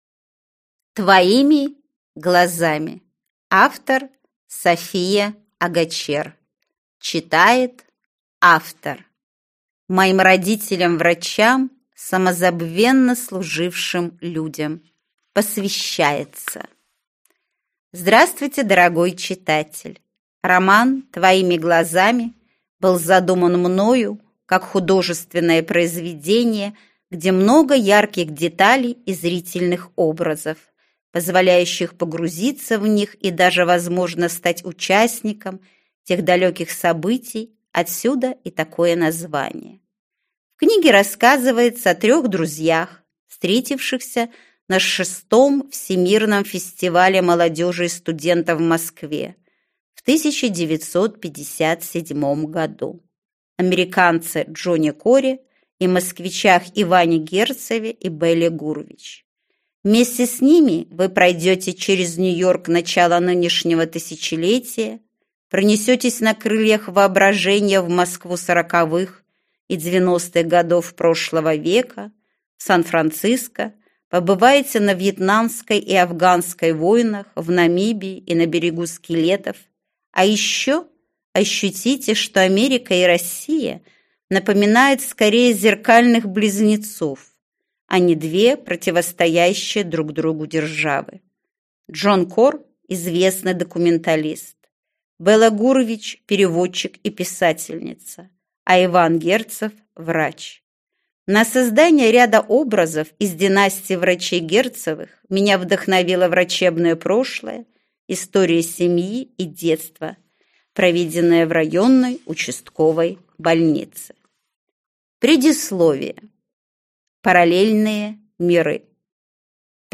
Аудиокнига Твоими глазами | Библиотека аудиокниг